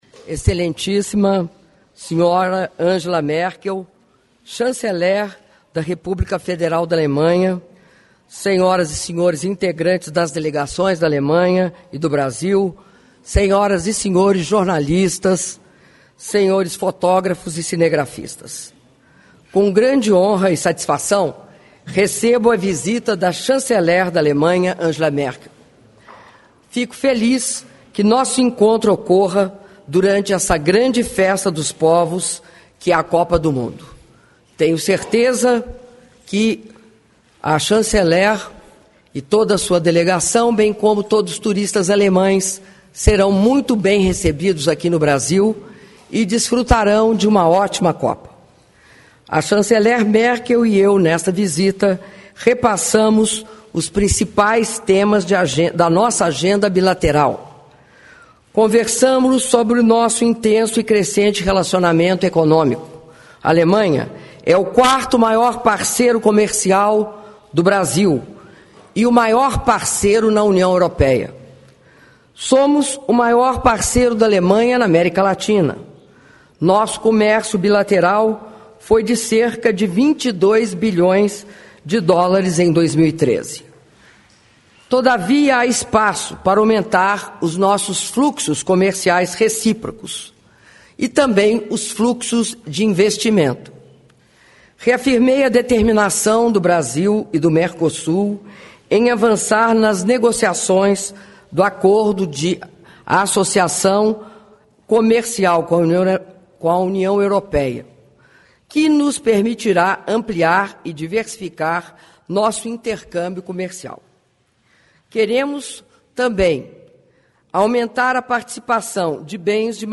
Áudio da declaração à imprensa da Presidenta da República, Dilma Rousseff, após reunião de trabalho com a Chanceler da Alemanha, Angela Merkel (06min55s)